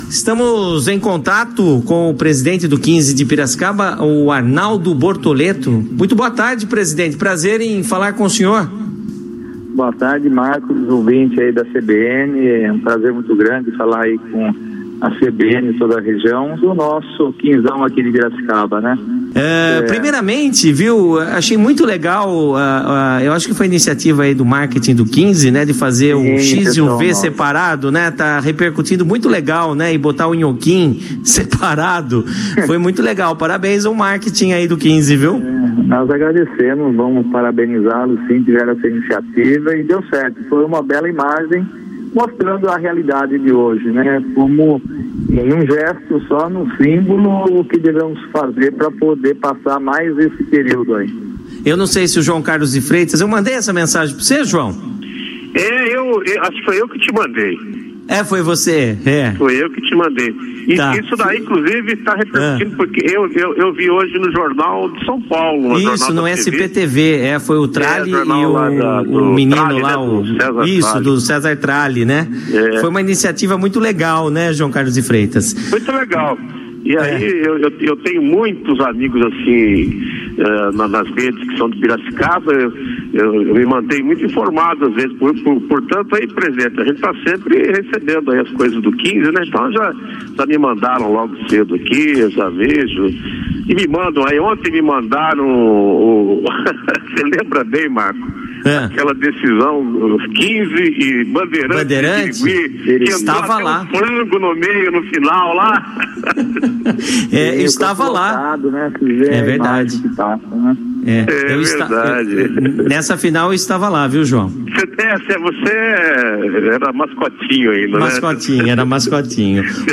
Em entrevista ao CBN Esportes